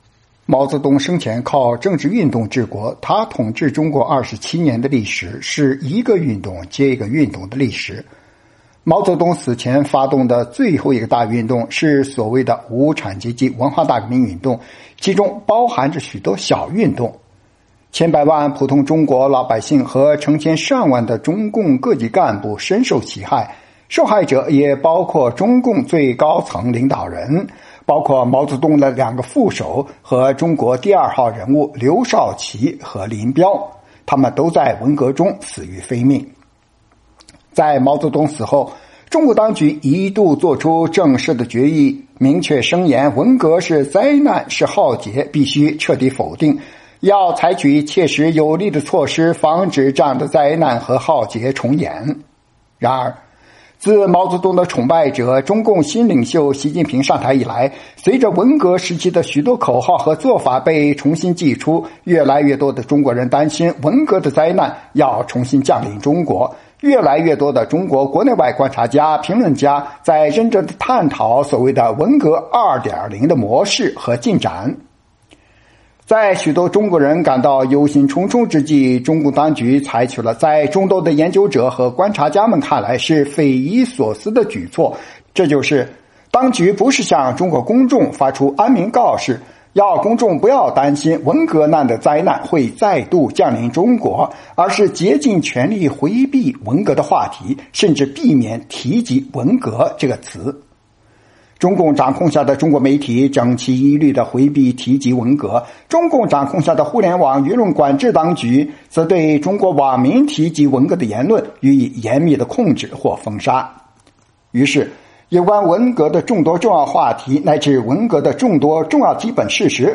专访学者胡平(1)：谈中共缘何对文革话题羞涩